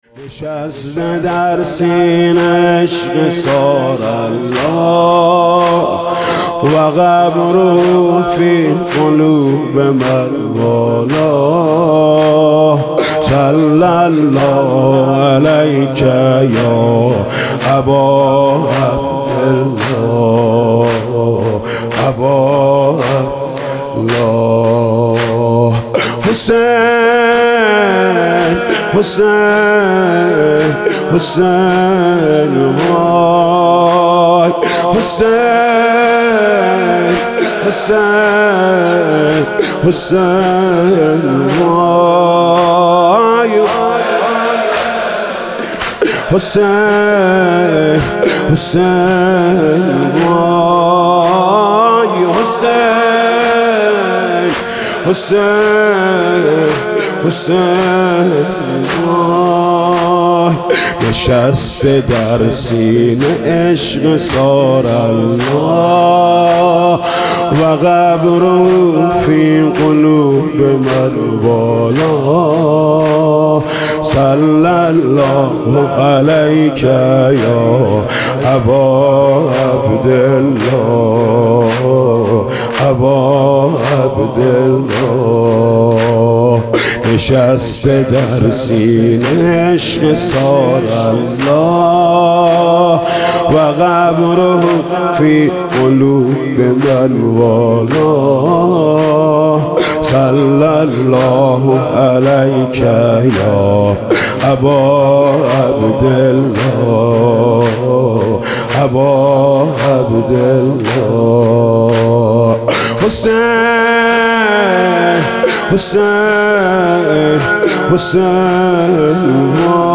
خیمه گاه - عاشقان اهل بیت - اربعین96- زمینه- نشسته در سینه عشق ثارالله- حاج محمود کریمی